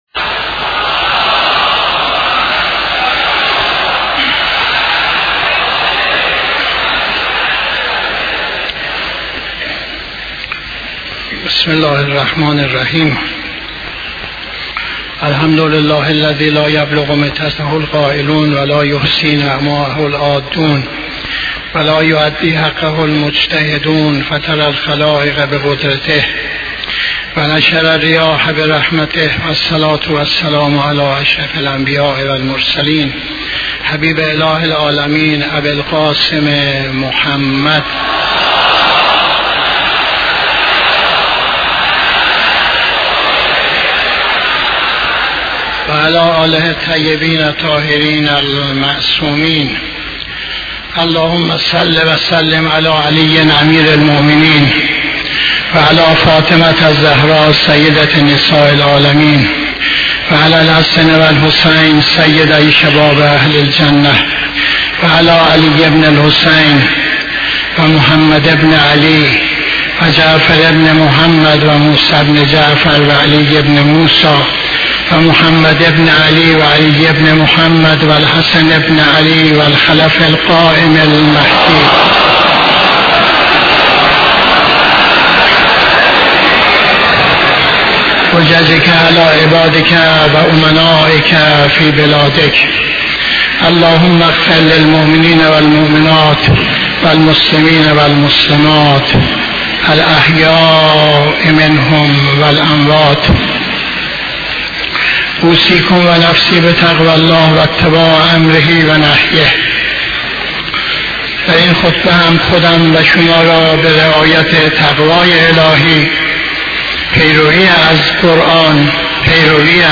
خطبه دوم نماز جمعه 23-10-84